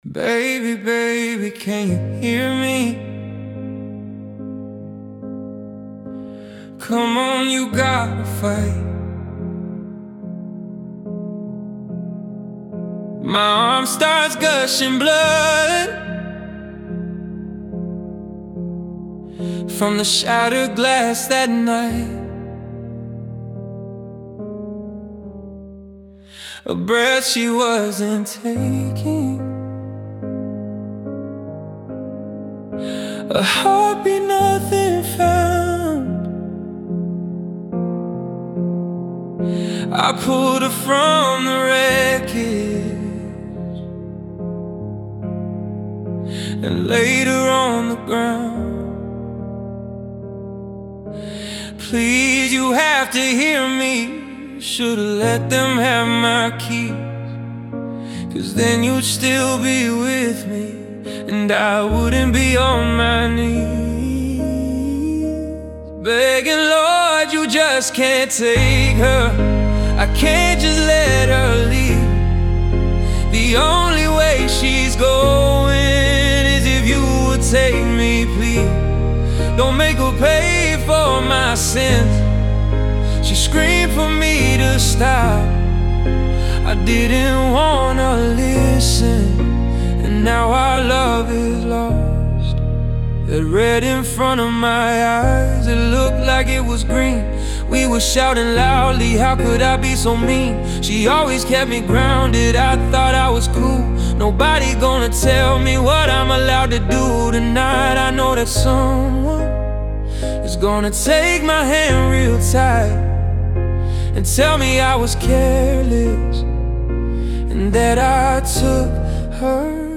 Category: R&B